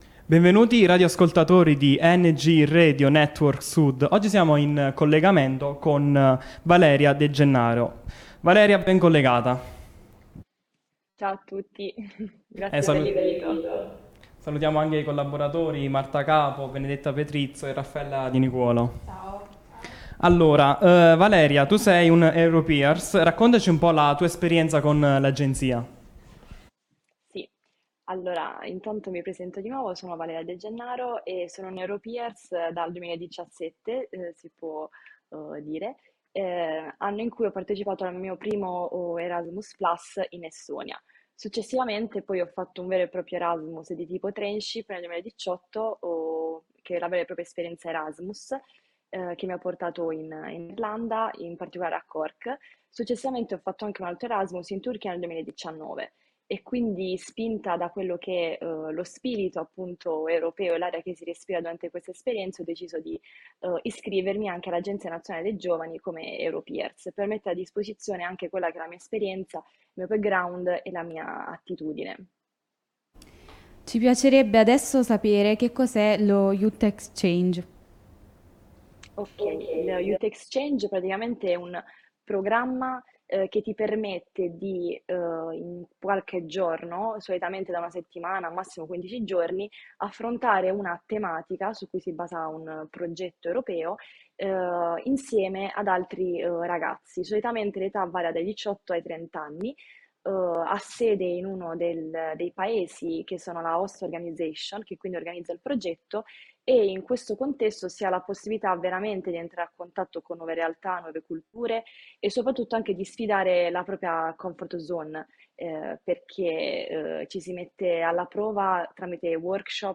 intervistata dai giovani di Ang In Radio Network Sud